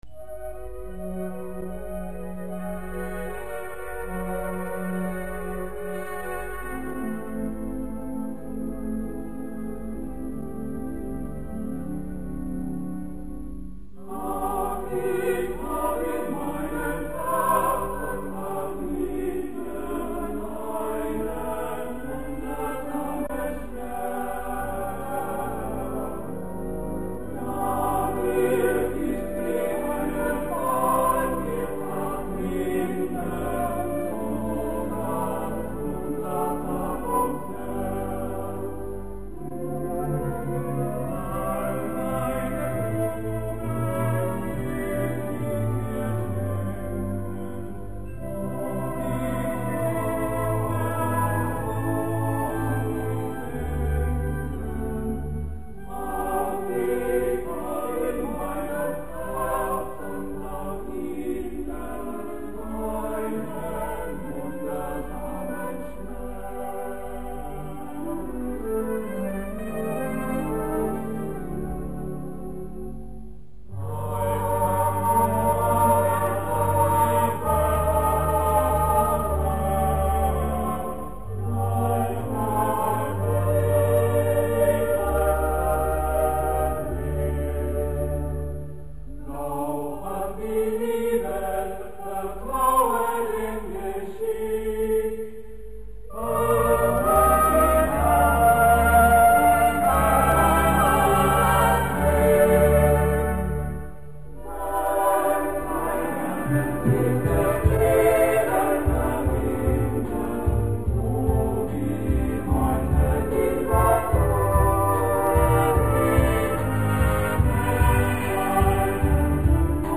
Gattung: Mit Gesang und CD
Besetzung: Blasorchester